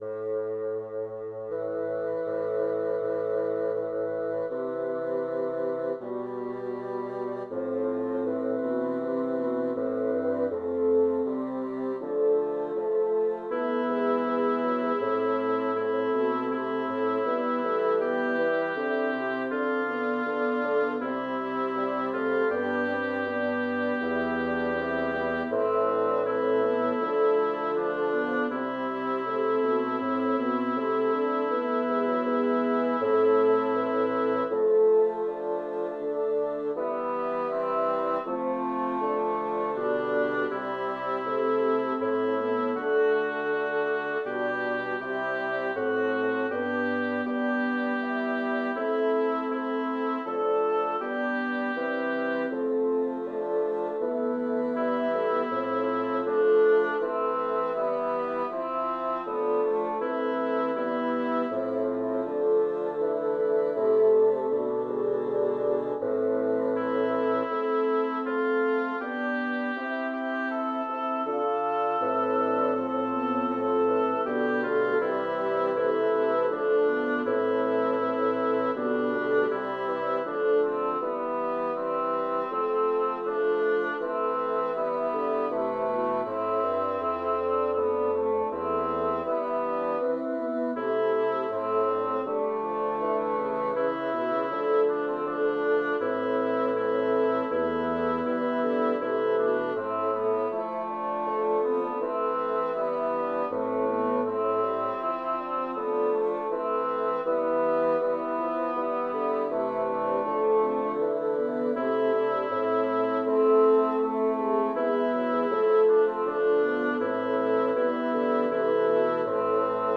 Title: Iudica, Domine, nocentes me Composer: Francesco Stivori Lyricist: Number of voices: 5vv Voicing: ATTTB Genre: Sacred, Motet
Language: Latin Instruments: A cappella